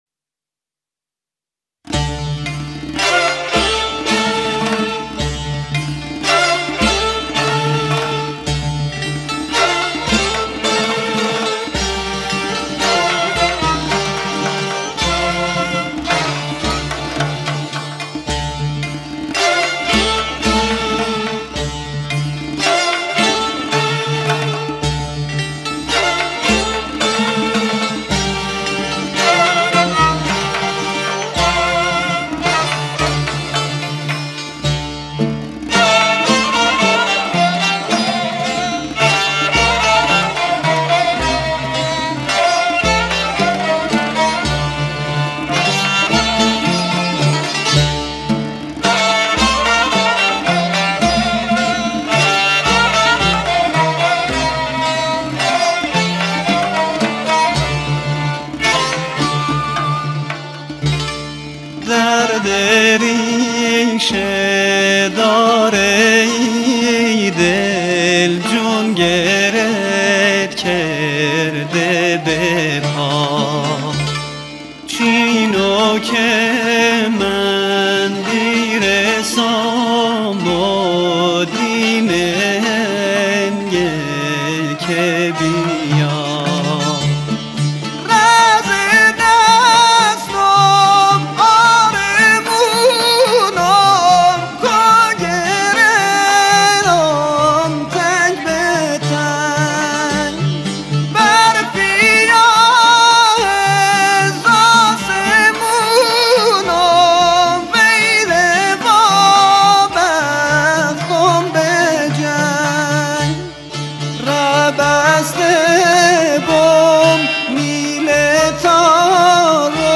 بختیاری